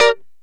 Lng Gtr Chik Min 03-G2.wav